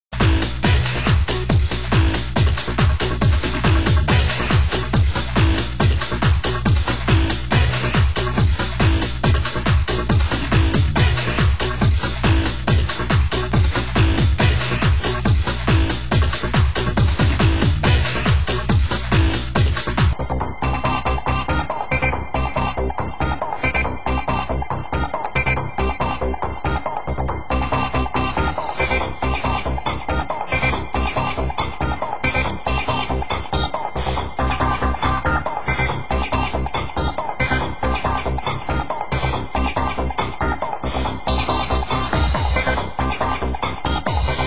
Genre: Hard House